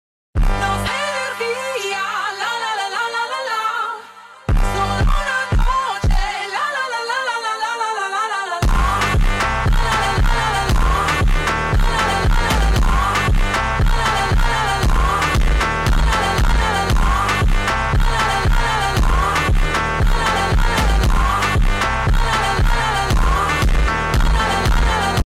Fendt 516 Vario mit Pöttinger sound effects free download